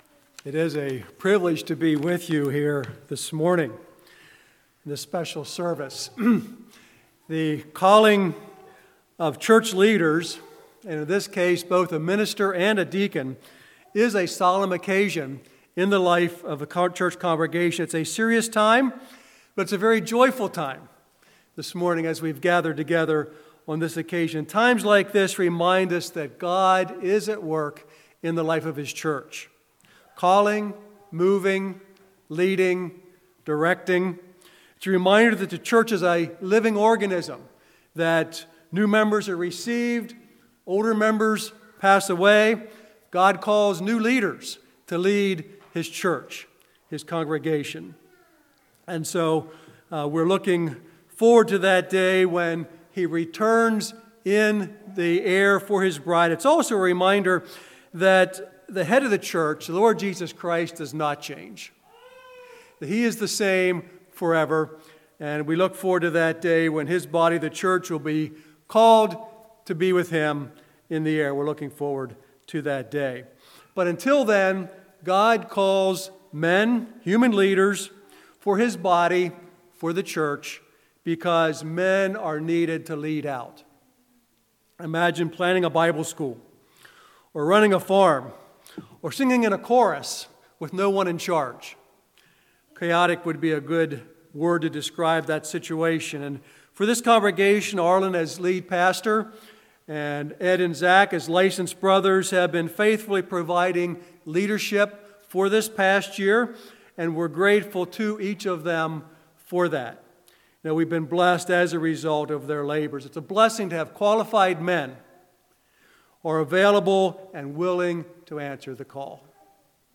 Ordination Message